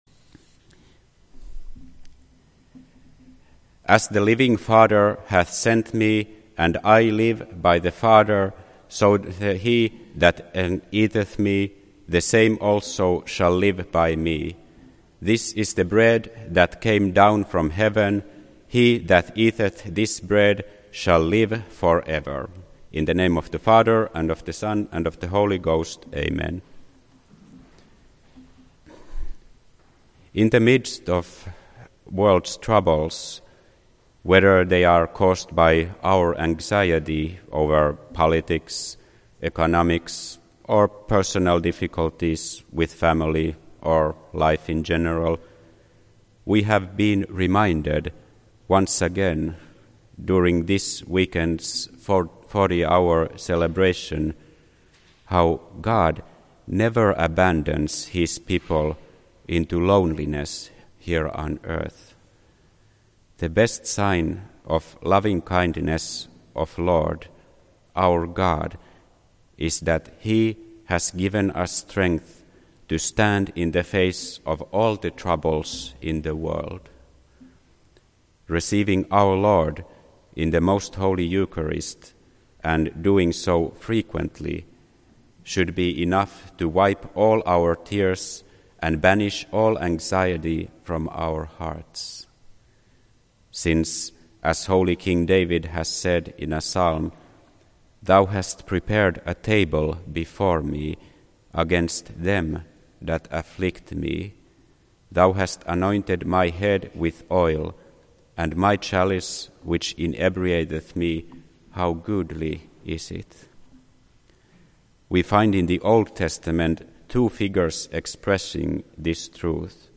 This entry was posted on Sunday, November 18th, 2012 at 11:22 pm and is filed under Sermons.